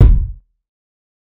TC2 Kicks22.wav